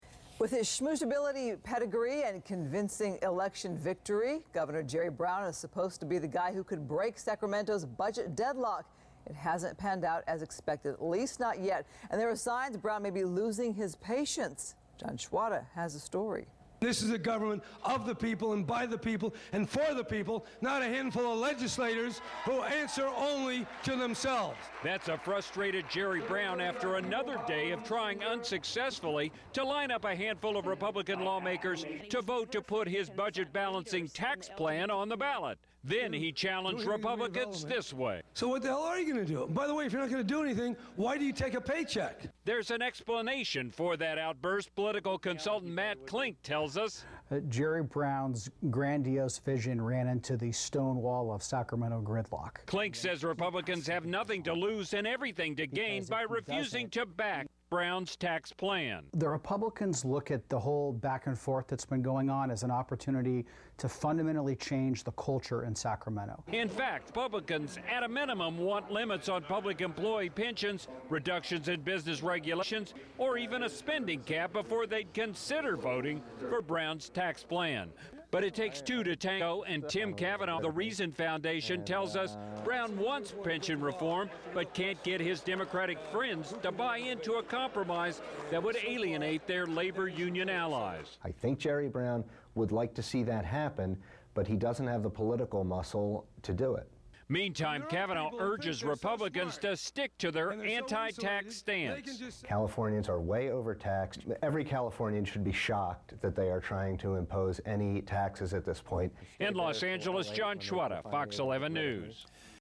appeared on Fox 11 News to discuss California governor Jerry Brown's latest troubles in the effort to repair California's budget.